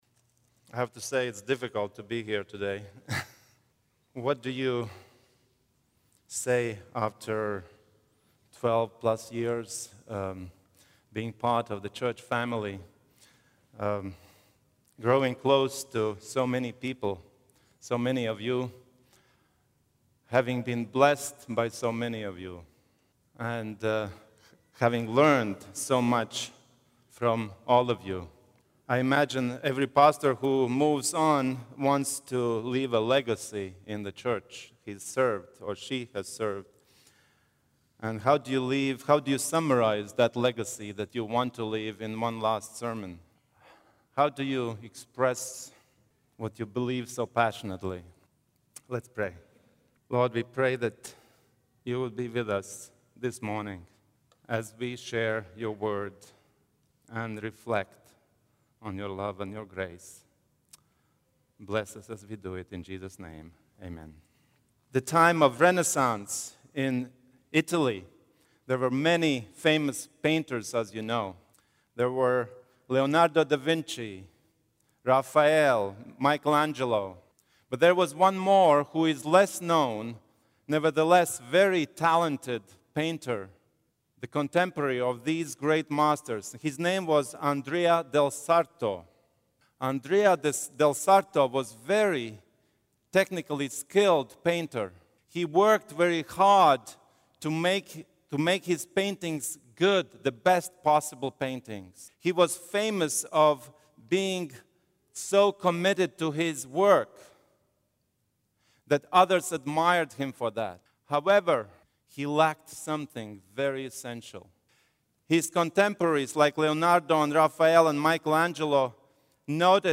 final sermon at Vallejo